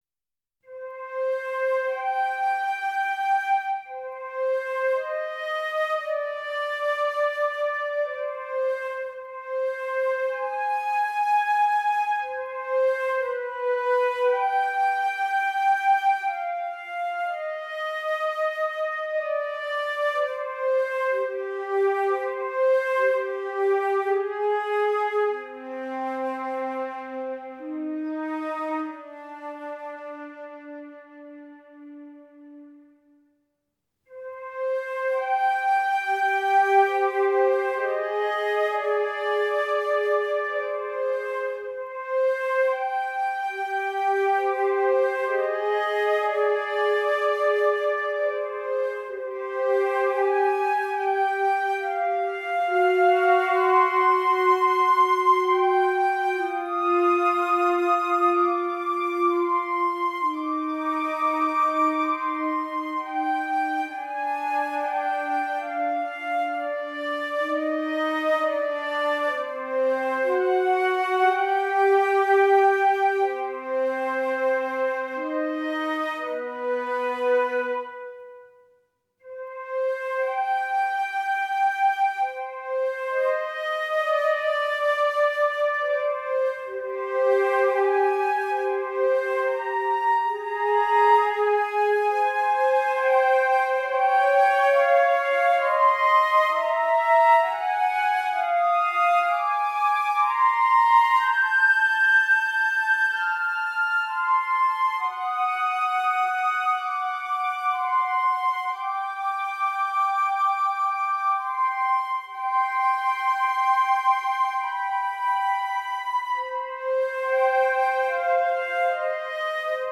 string orchestra
trumpets
transverse flutes
percussion
clarinet
orchestral work in 7 pieces (21:15)